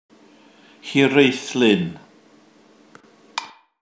To hear how to pronounce Hiraethlyn, press play: